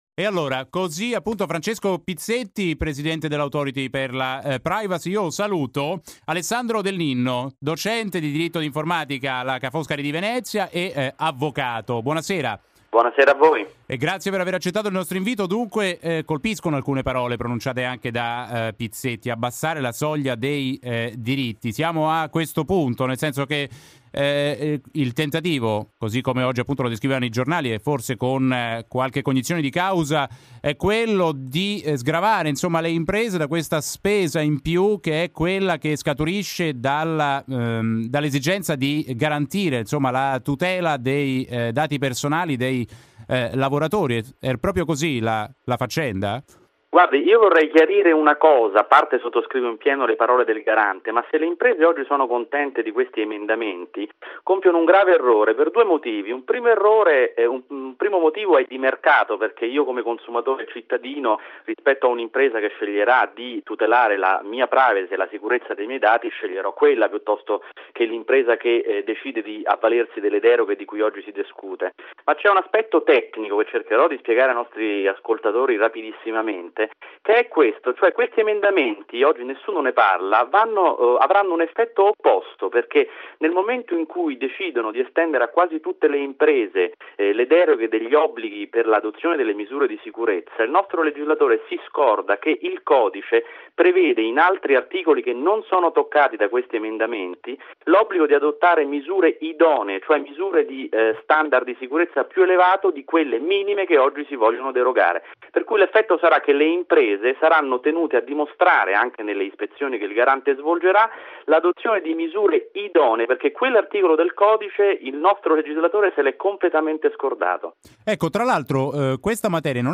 Intervista radiofonica su diritto d'autore e Internet (Radio Città Futura)